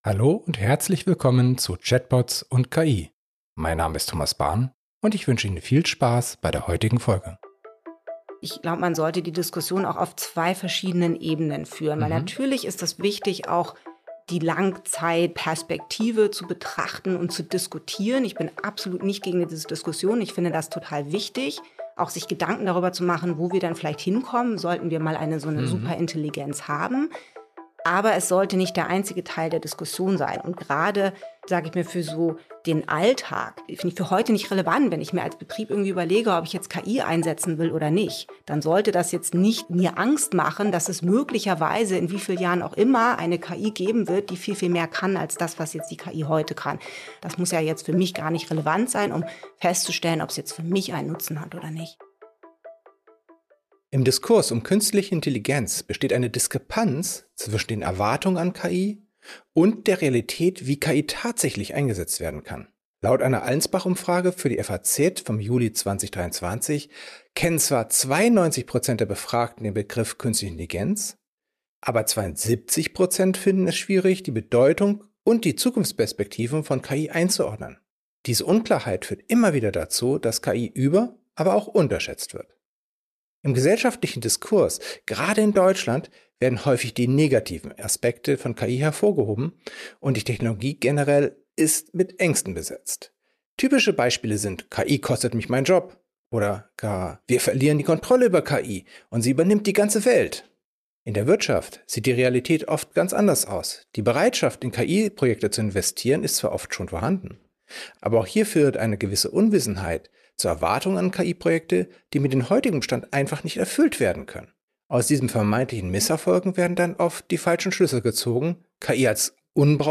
Im ersten Teil des Interviews